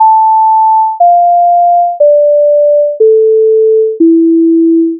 reminder_melody2
reminder_melody2.wav